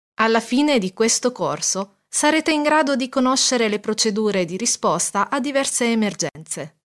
意大利语翻译团队成员主要由中国籍和意大利籍的中意母语译员组成，可以提供证件类翻译（例如，驾照翻译、出生证翻译、房产证翻译，学位证翻译，毕业证翻译、成绩单翻译、无犯罪记录翻译、营业执照翻译、结婚证翻译、离婚证翻译、户口本翻译、奖状翻译等）、公证书翻译、病历翻译、意语视频翻译（听译）、意语语音文件翻译（听译）、技术文件翻译、工程文件翻译、合同翻译、审计报告翻译等；意大利语配音团队由意大利籍的意大利语母语配音员组成，可以提供意大利语专题配音、意大利语广告配音、意大利语教材配音、意大利语电子读物配音、意大利语产品资料配音、意大利语宣传片配音、意大利语彩铃配音等。
意大利语样音试听下载